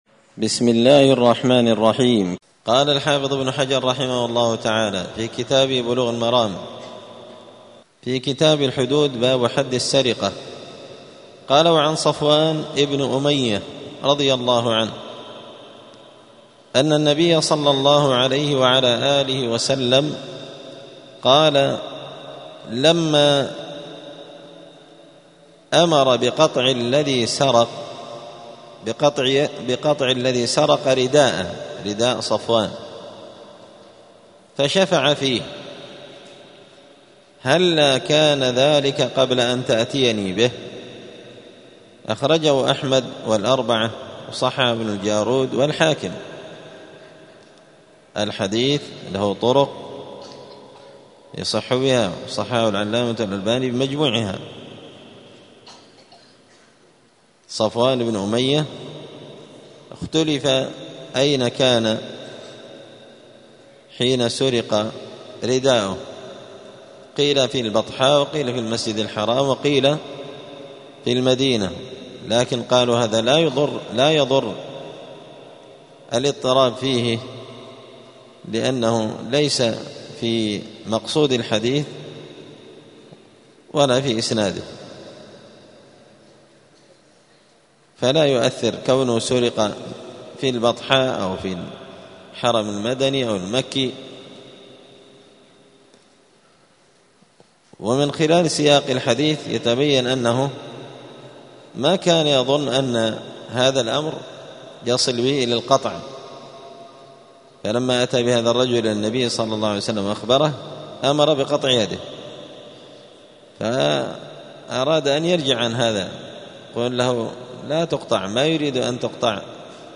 *الدرس السابع والعشرون (27) {باب حد السرقة الحرز وشروطه وحكم النباش}*